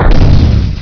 railexpl.wav